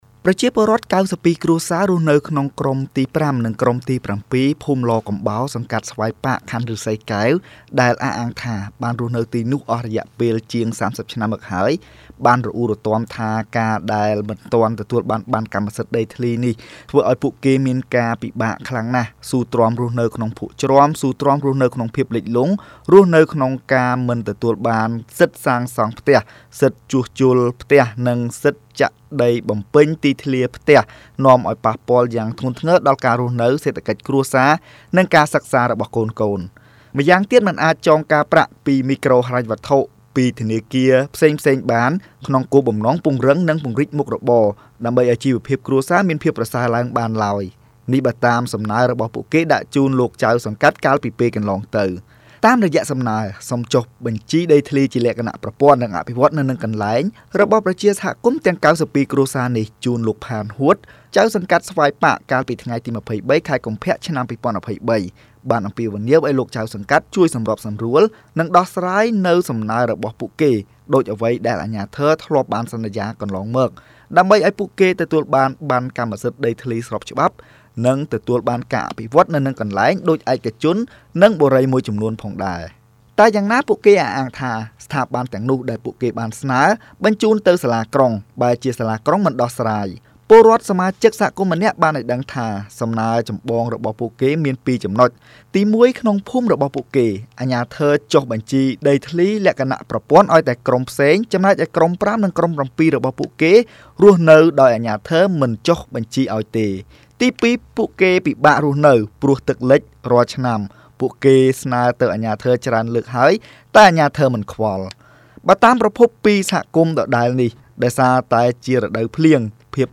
រាយការណ៍